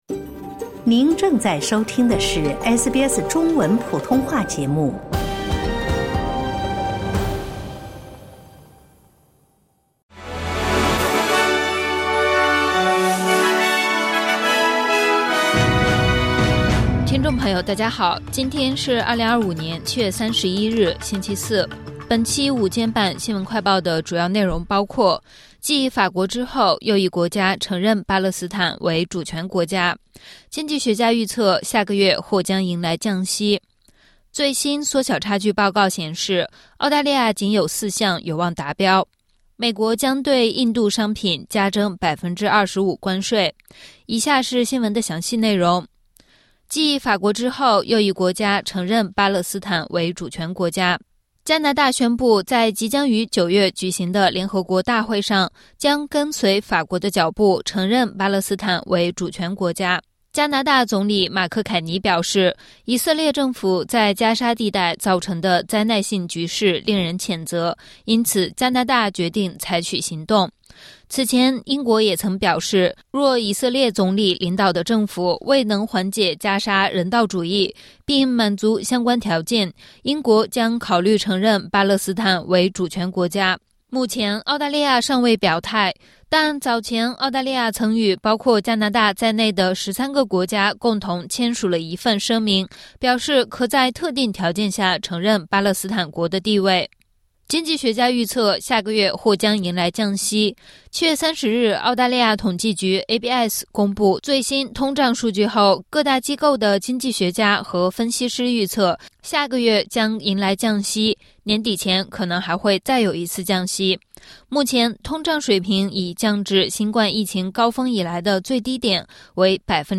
【SBS新闻快报】继法国之后 又一国家承认巴勒斯坦国